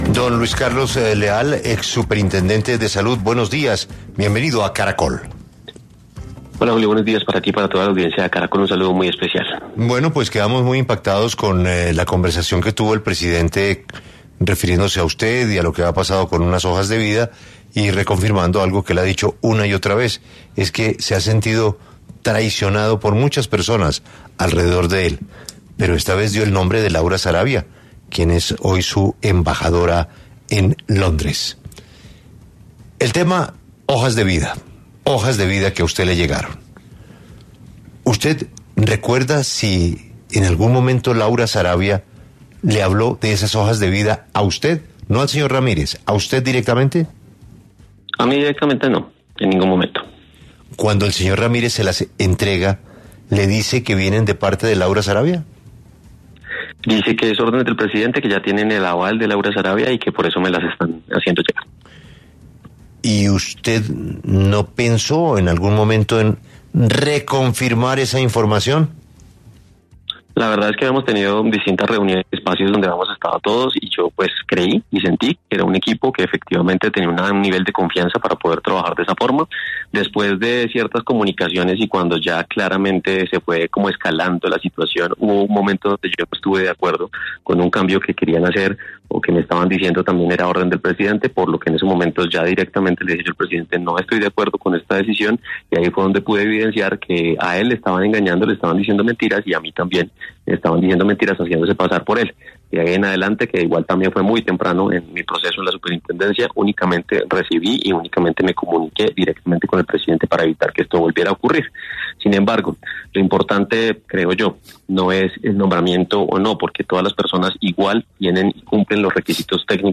Este viernes, 30 de enero, en los micrófonos de 6AM W, de Caracol Radio con Julio Sánchez Cristo, habló el ex superintendente de Salud Luis Carlos Leal, quien se pronunció por la confirmación que hizo el presidente Gustavo Petro sobre que la embajadora Laura Sarabia habría sugerido algunas hojas de vida para administrar, presuntamente, las EPS intervenidas.